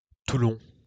Toulon (UK: /ˈtlɒ̃/, US: /tˈln, -ˈlɔːn, -ˈlɒn/,[3][4][5][6] French: [tulɔ̃]